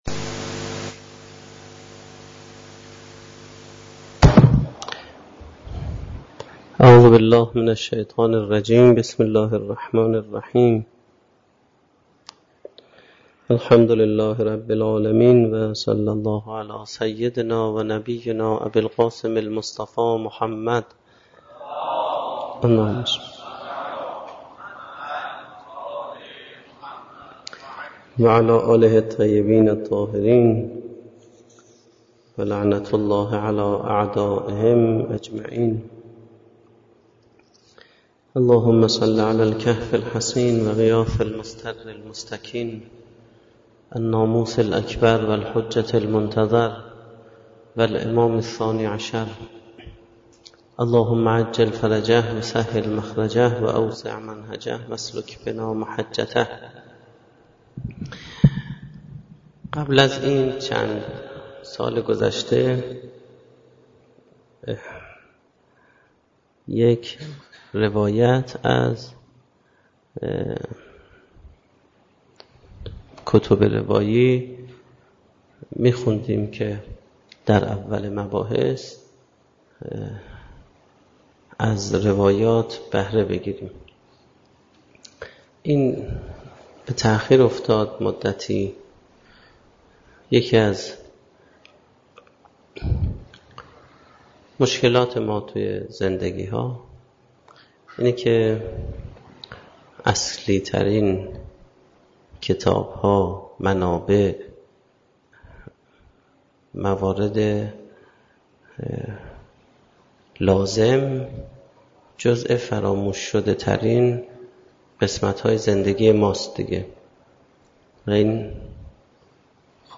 سخنرانی